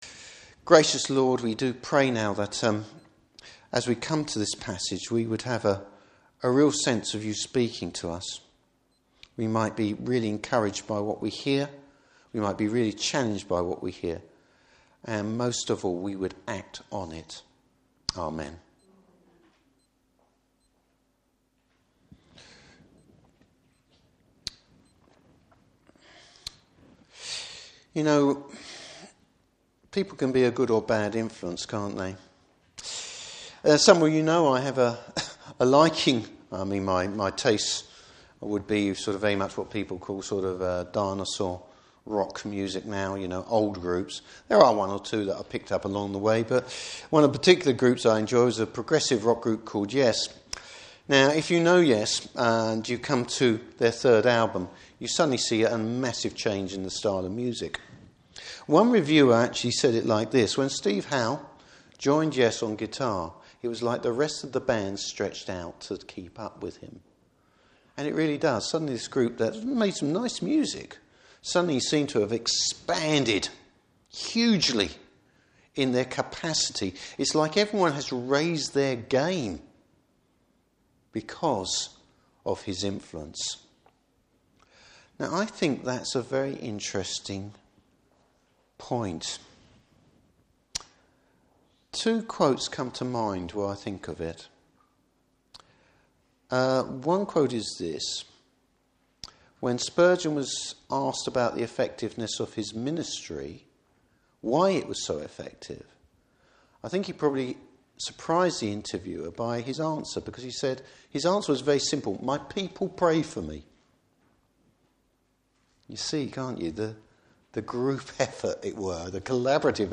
Service Type: Morning Service Bible Text: Luke 17:1-10.